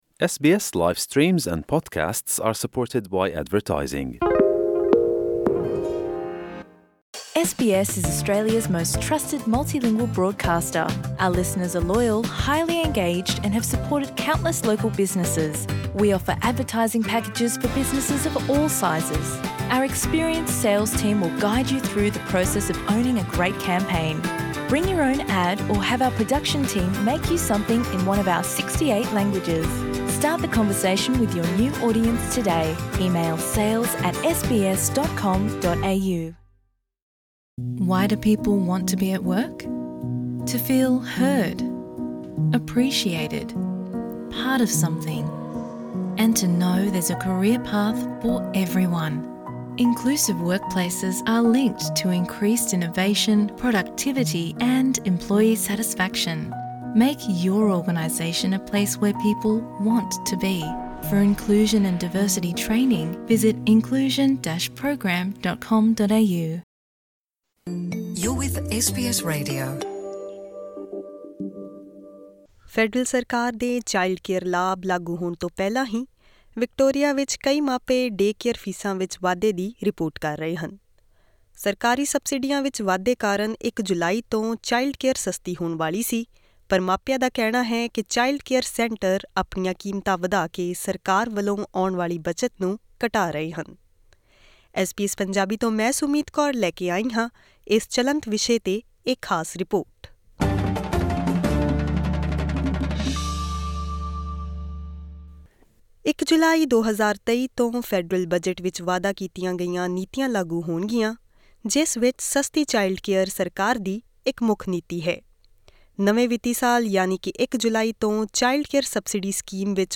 ਹੋਰ ਵੇਰਵੇ ਲਈ ਇਹ ਆਡੀਓ ਰਿਪੋਰਟ ਸੁਣੋ.......